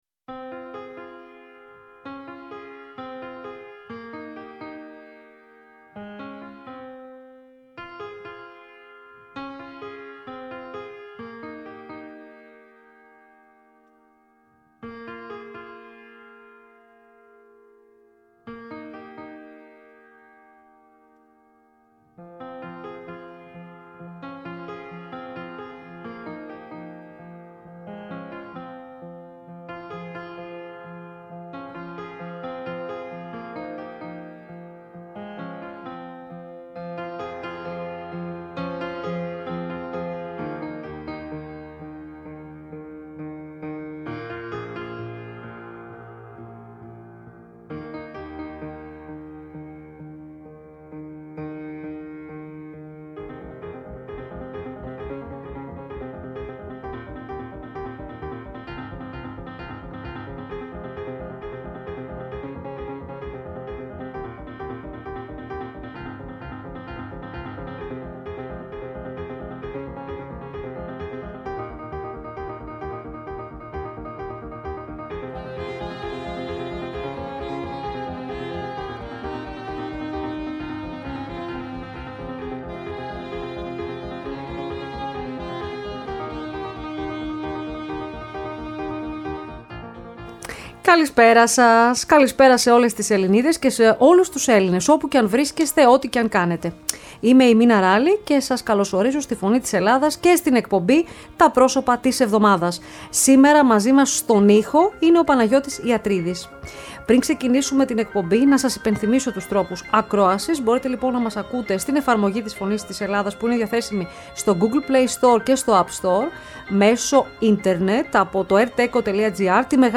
Τα Προσωπα Της Εβδομαδας ΣΥΝΕΝΤΕΥΞΕΙΣ